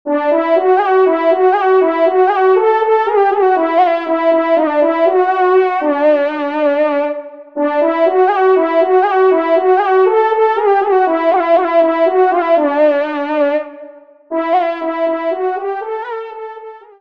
Genre :  Fanfare de circonstances